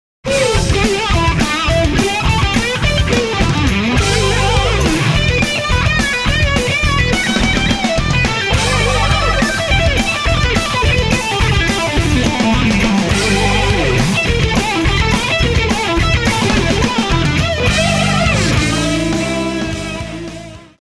Phasing
The Small Stone´s full bodied, three dimensional phasing adds a special swirl to every musical style.
Metal-heads and Industrialists dig the Stone's jet plane woosh.
Download the demo jplayed by Paul Gilbert from Mister Big: